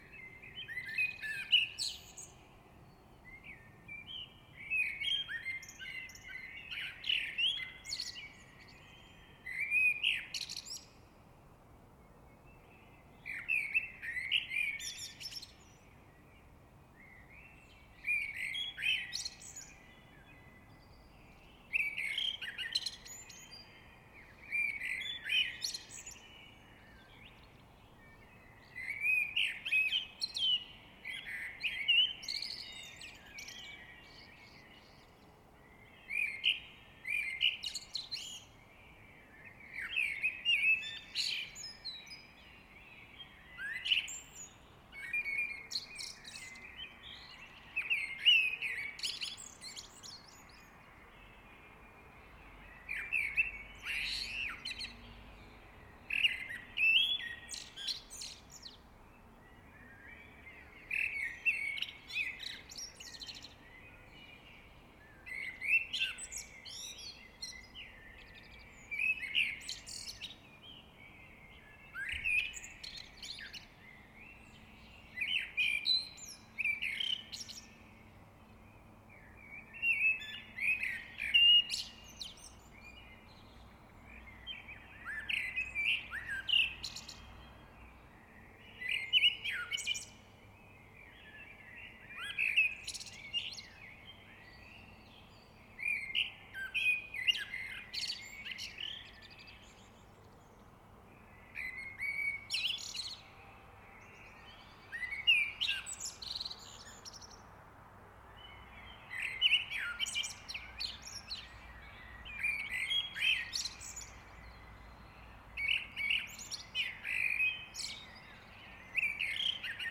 Ich liebe dieses "Düdeldüdeldüdel" bei 0:44, 02:44, 04:49 und 07:25. Es klingt, als hätte die Amsel einen Schwips! 😆🍷
There was a lot of background noise that I had to cut out.
I love this funny motif at 0:44, 02:44, 04:49 and 07:25. It sounds like the blackbird is tipsy! 😆🍷